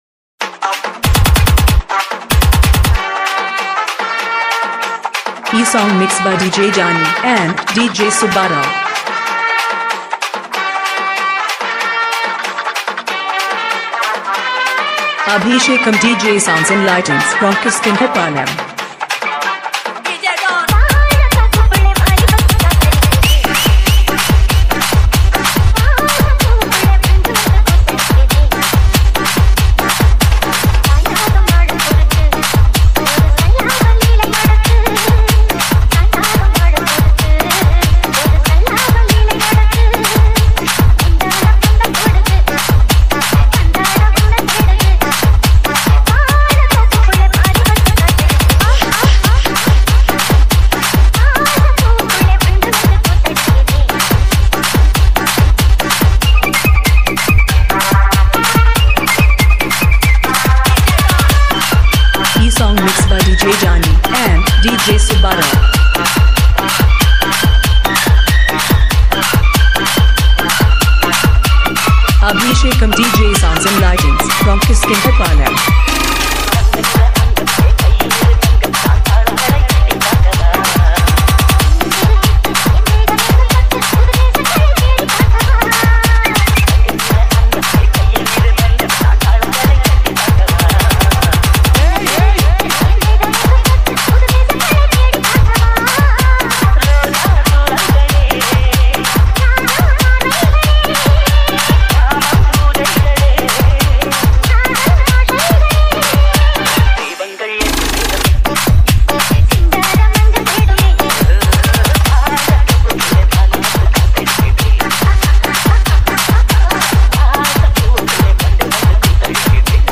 ALL TAMIL ROMANTIC DJ REMIX